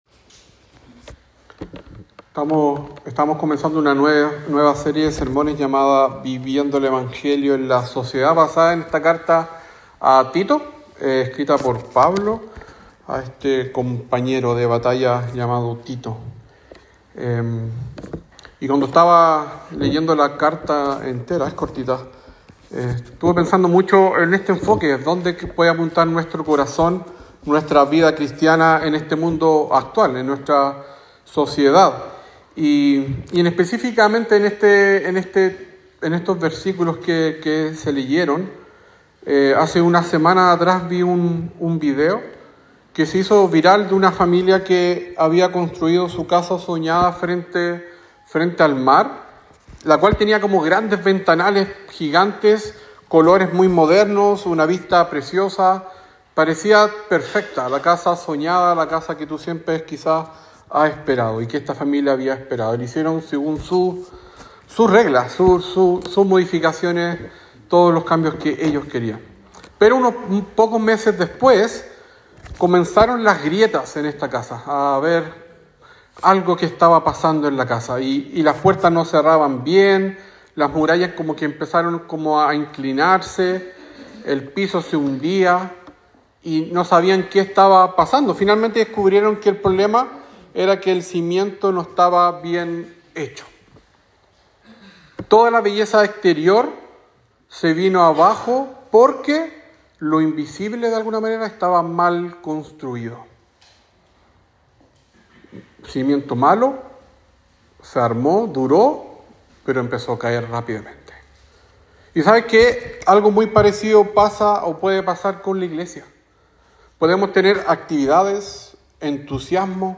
Sermón sobre Tito 1 : 1 - 9